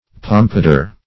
Pompadour \Pom"pa*dour\, n.